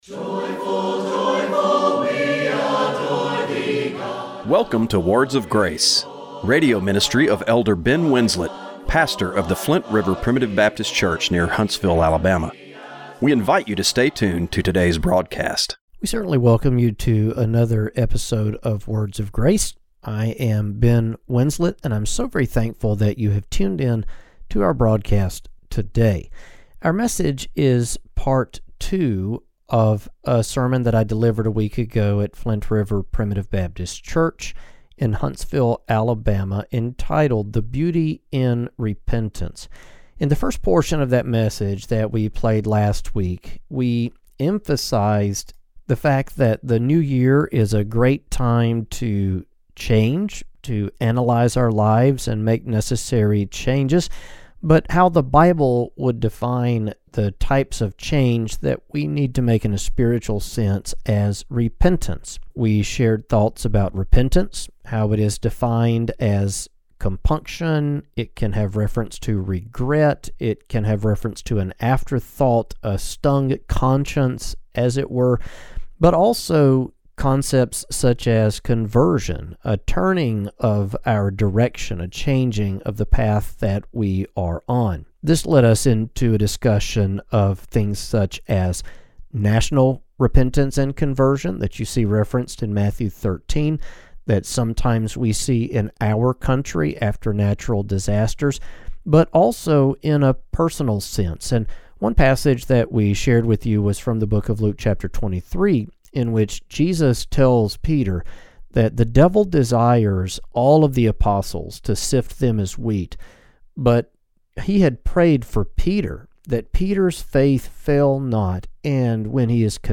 Radio broadcast for January 18, 2026.